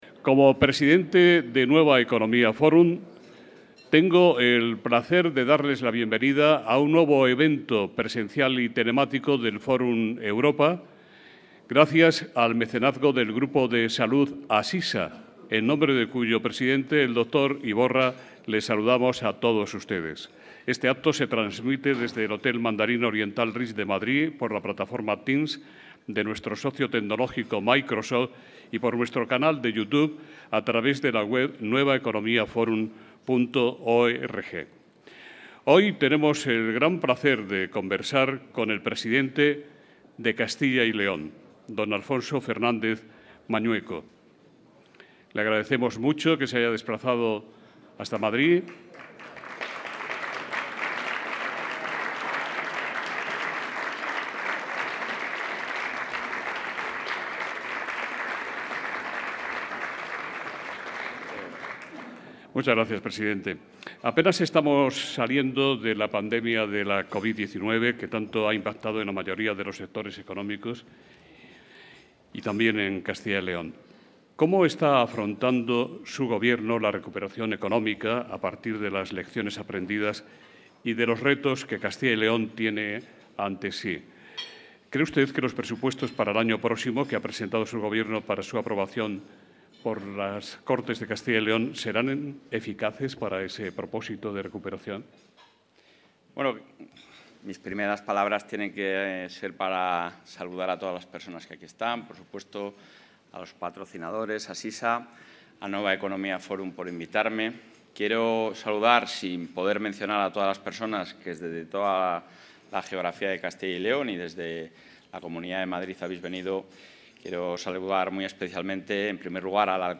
Intervención del presidente.
El presidente de la Junta de Castilla y León, Alfonso Fernández Mañueco, ha defendido hoy, durante su participación en el desayuno informativo de Nueva Economía Forum celebrado en Madrid, que la economía de la Comunidad está bien posicionada de cara a la recuperación. Ya durante la pandemia, ha recordado, se mantuvieron los datos de PIB (Producto Interior Bruto) y de déficit por debajo de la media nacional y ahora la Comunidad está cerca de lograr el hito histórico del millón de ocupados.